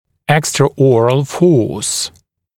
[ˌekstrə’ɔːrəl fɔːs] [ˌэкстрэ’о:рэл фо:с] внеротовая сила